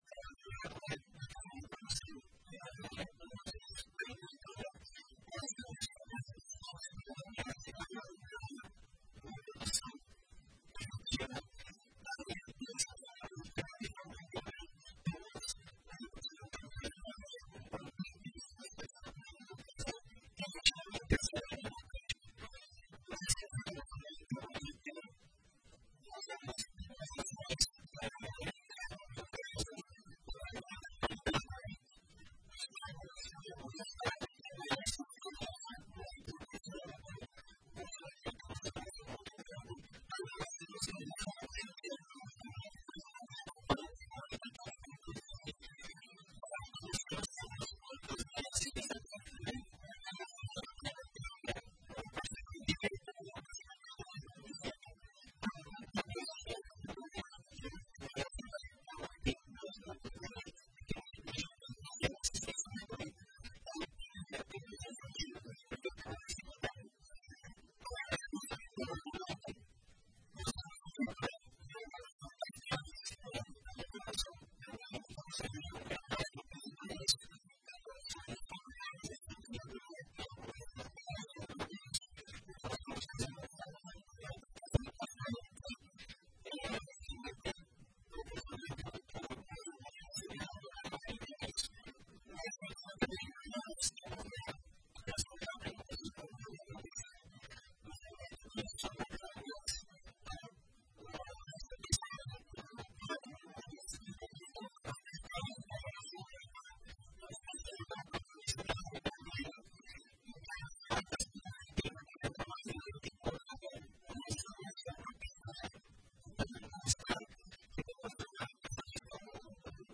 Ouça abaixo uma parte da entrevista concedida pelo secretário Emerson Pereira nesta manhã (09) no programa Rádio Ligado: